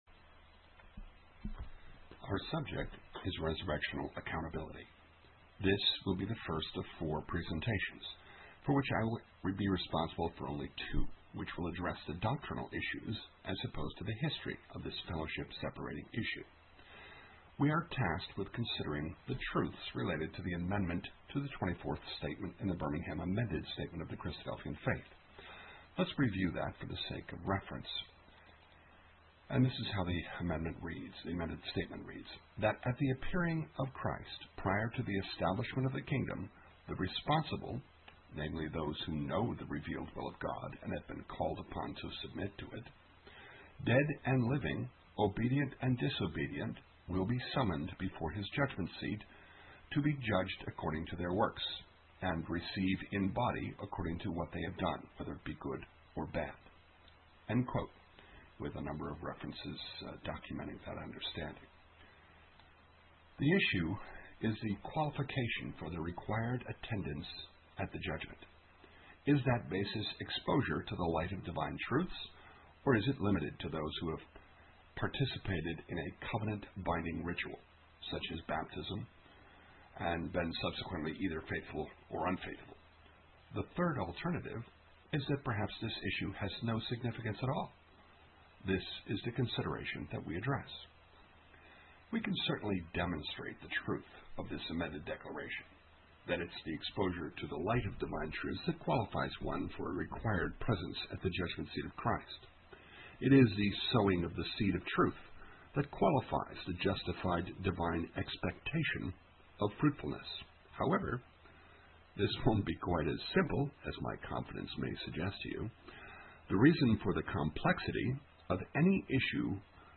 Resurrectional Accountability 1: Audio resurrectional Accountability Class 1